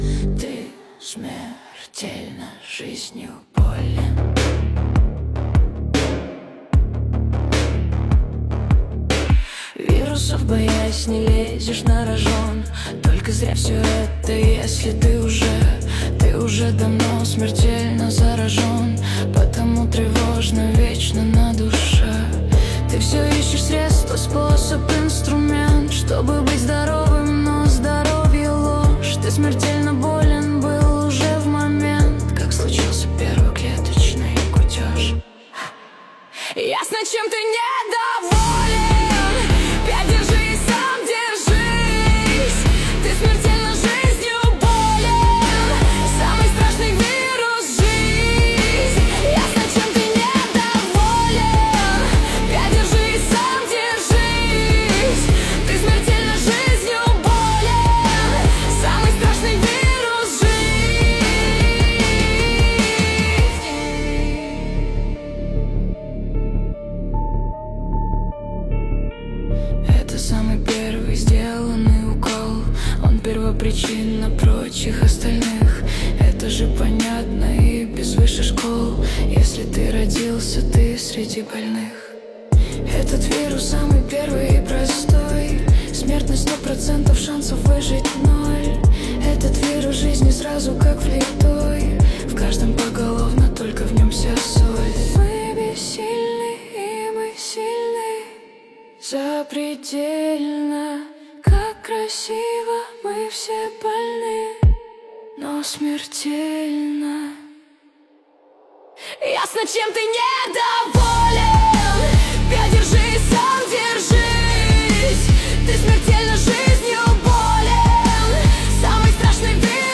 Плейлисты: Suno Ai (нейросеть)
Качество: 320 kbps, stereo